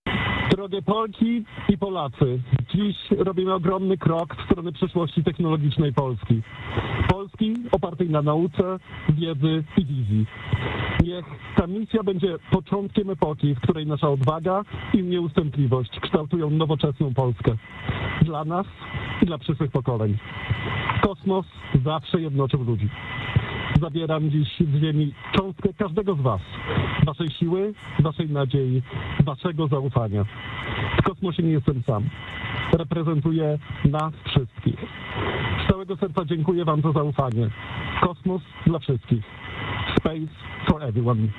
Pierwsze słowa dr Uznańskiego – Wiśniewskiego z kapsuły Dragon
Krótko po starcie nastąpiło łączenie z załogą rakiety Falcon 9. Dr Sławosz Uznański – Wiśniewski powiedział kilka słów, które skierował do Polaków.